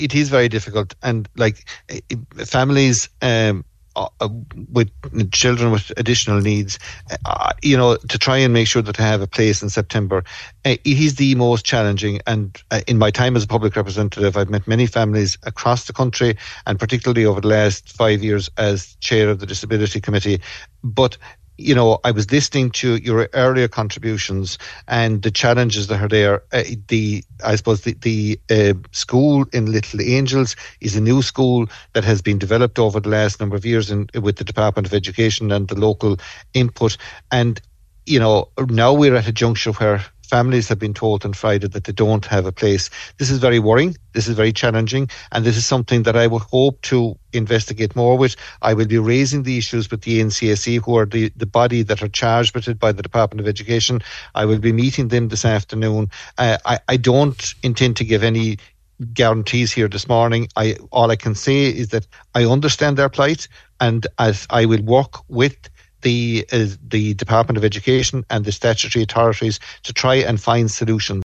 Today on the Nine til Noon Show, Minister Michael Moynihan said he will be meeting today with the National Council for Special Education to discuss how to resolve the issue……………..